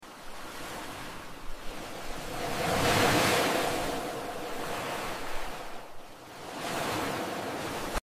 Satisfying ASMR videos of Dollar's/Money